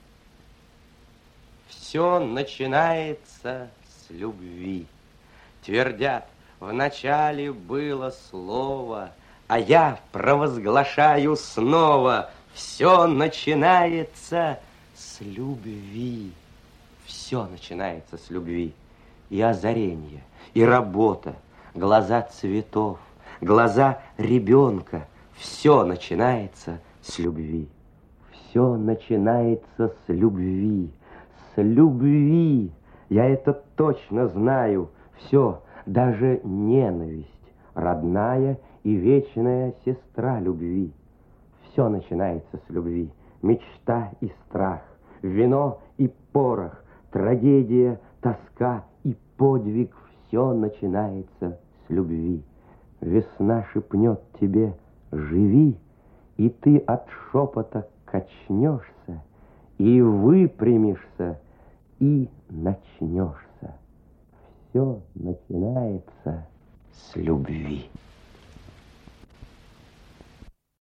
chit-nikolaj-karachentsov-r-rozhdestvenskij-vse-nachinaetsya-s-lyubvi